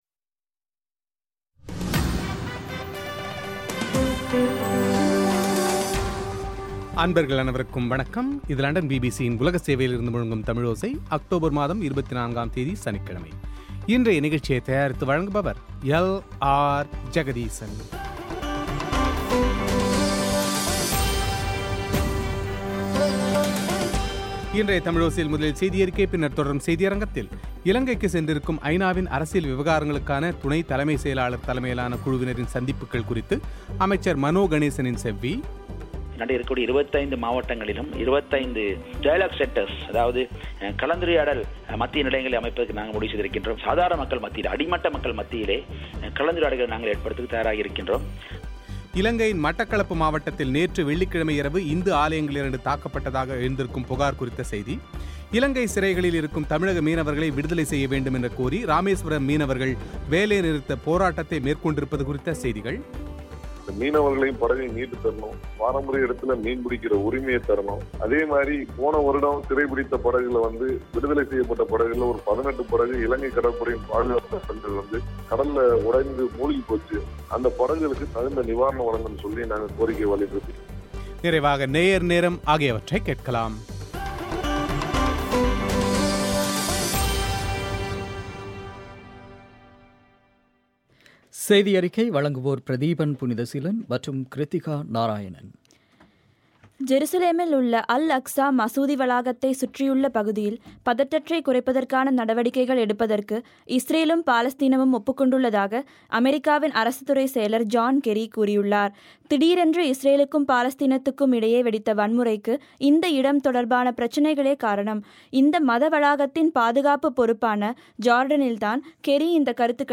இலங்கைக்கு சென்றுள்ள ஐநாவின் அரசியல் விவகாரங்களுக்கான துணை தலைமைச் செயலாளர் தலைமையிலான குழுவினரின் சந்திப்புகள் குறித்து அமைச்சர் மனோ கணேசனின் செவ்வி